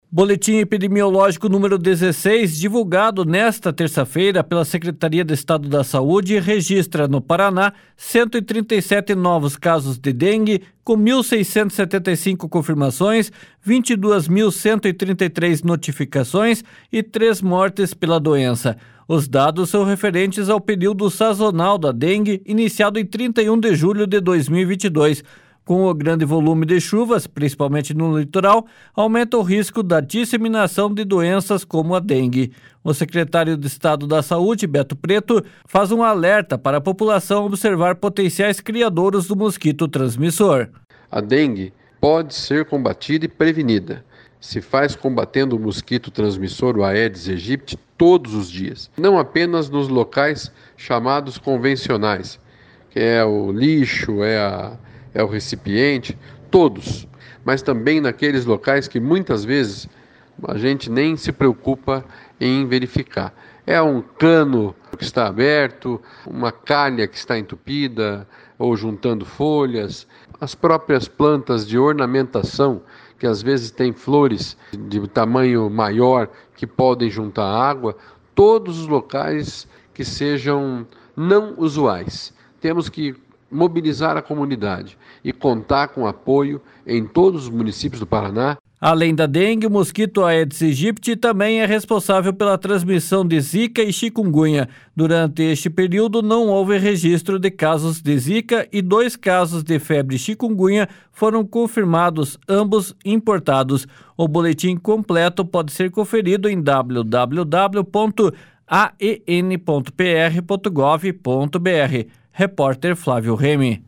O secretário de Estado da Saúde, Beto Preto, faz um alerta para a população observar potenciais criadouros do mosquito transmissor. //SONORA BETO PRETO//